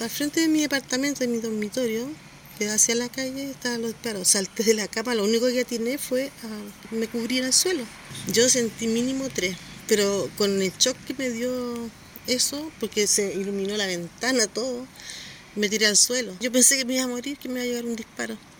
Una de las testigos de estos hechos relató que, si bien estaba en un departamento, igualmente se tiró al suelo pensando en que podía resultar baleada.
cu-vecina-balacera.mp3